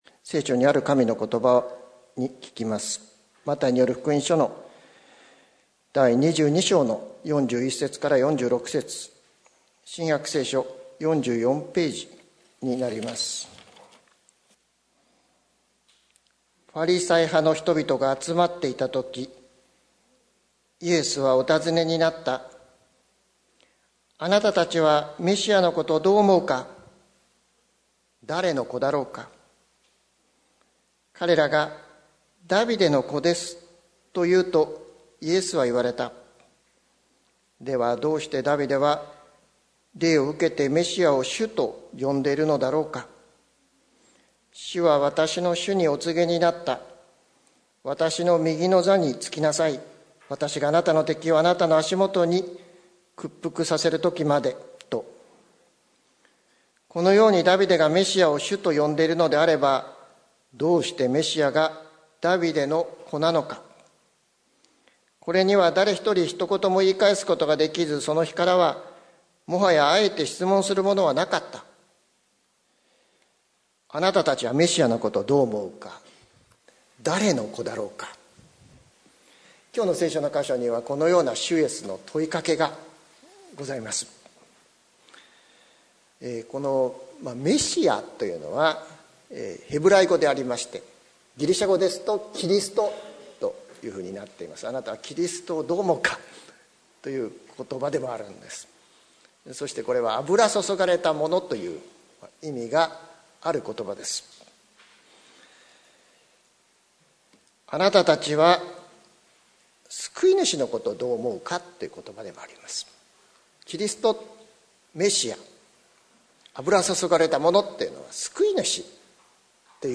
2020年10月18日朝の礼拝「あなたを救うのは誰ですか」関キリスト教会
説教アーカイブ。